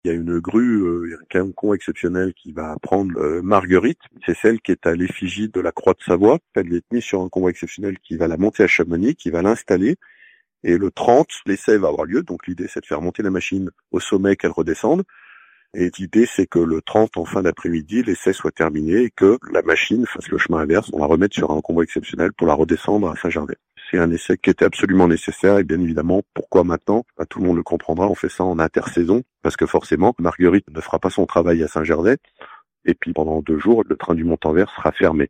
Martial Saddier, le président du Conseil départemental, explique à quoi s'attendre sur les routes.